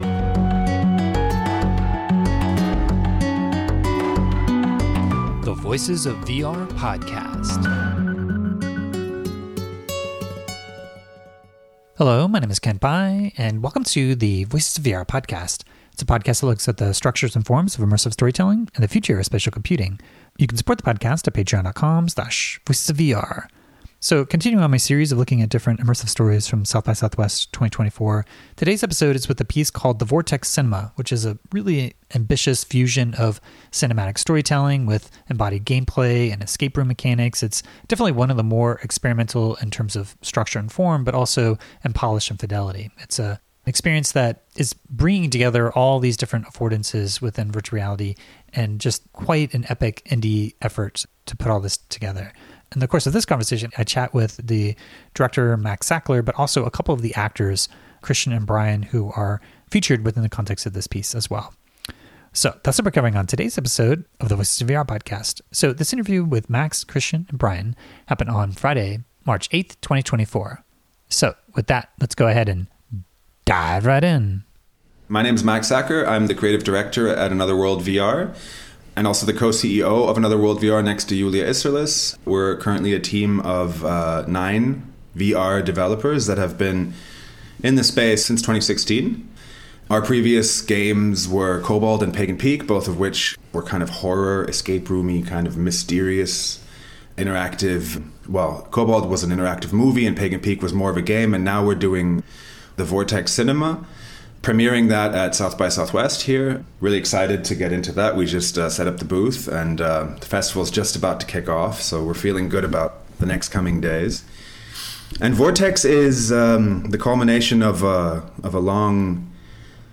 remotely ahead of the SXSW XR Experience 2024